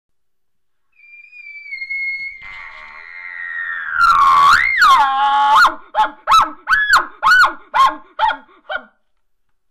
Bull Elk Sounds The Bull Elk make the most magnificent and powerful sounds. This is done to chase off, challenge, and establish dominance over the other Bull Elk. The Lip Bawl